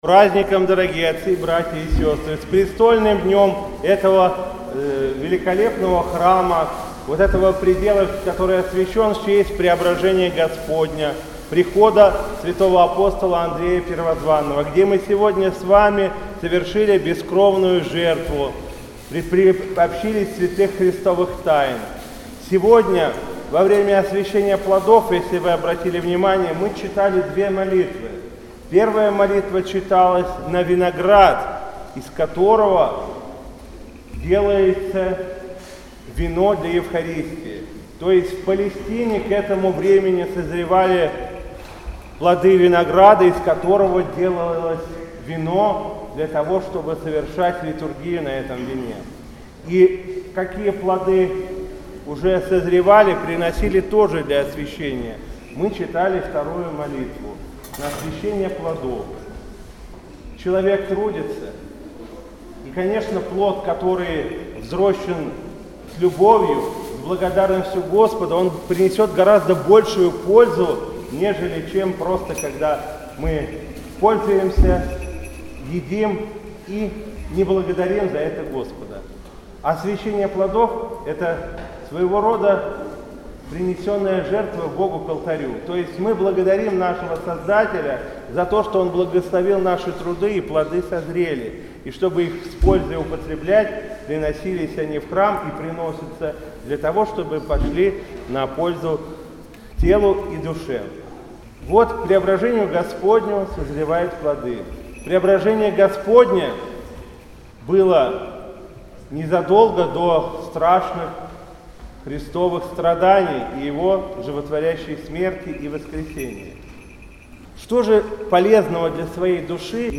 По окончании Божественной литургии митрополит Вологодский и Кирилловский Игнатий по традиции совершил чин освящения плодов и обратился к присутствующим с архипастырским словом.
Слово после Литургии в праздник Преображения Господня